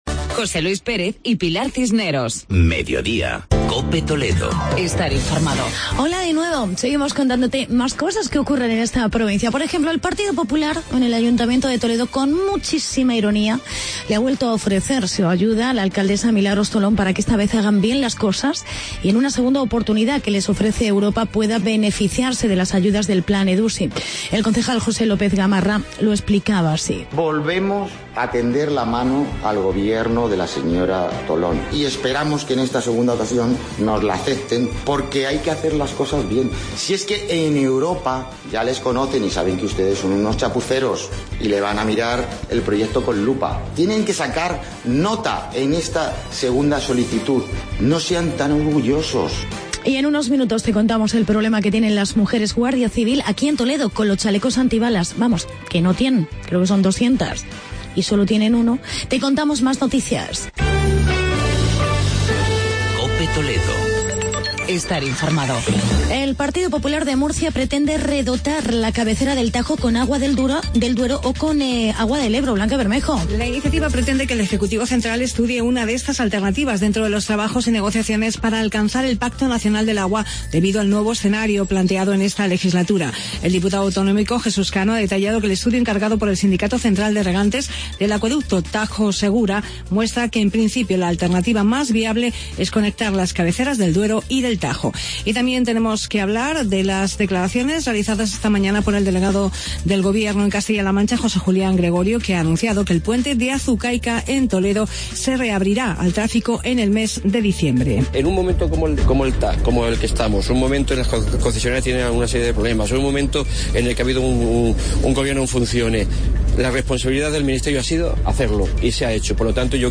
Actualidad y entrevista con La Asociación Unificada de la Guardia Civil sobre la falta de chalecos antibalas para las mujeres.